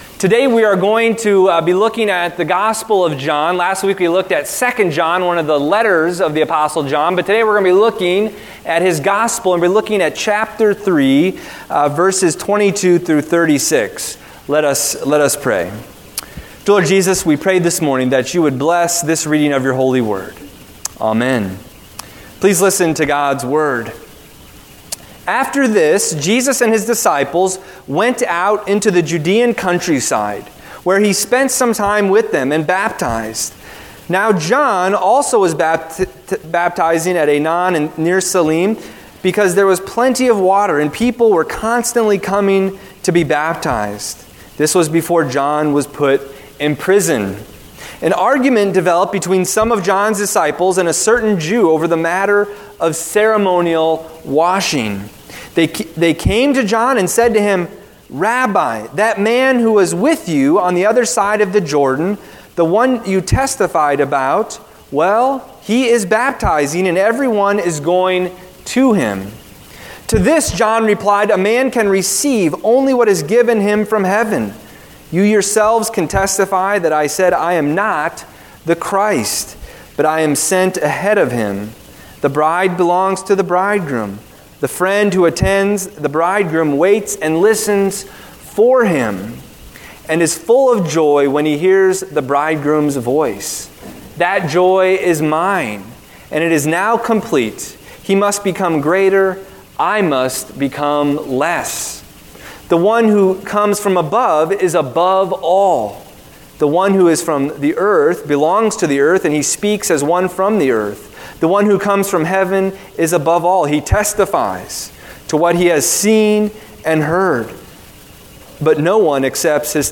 Recent Sermons Passage: John 3:22-36 Service Type: Sunday Morning